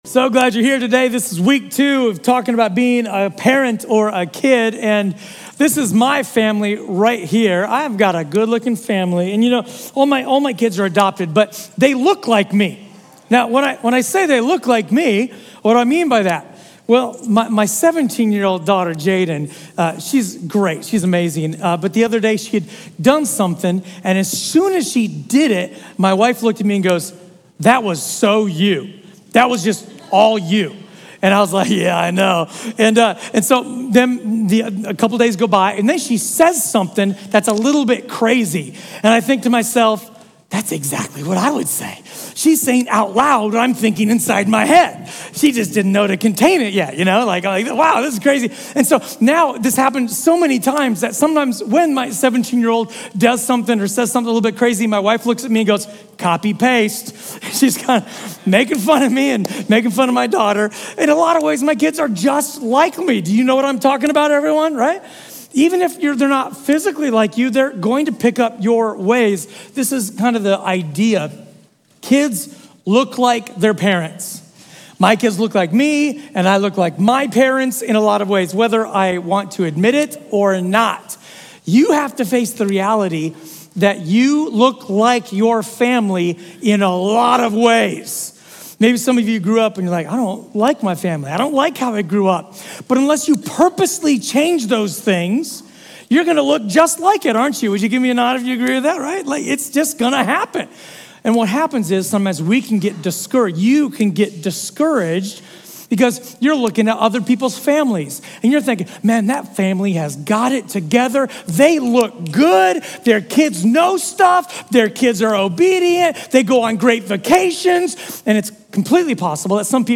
A sermon from the series "Revolution Sermon." Is your home growing good soil for your kids’ faith?